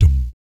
Index of /90_sSampleCDs/ILIO - Vocal Planet VOL-3 - Jazz & FX/Partition B/4 BASS THUMS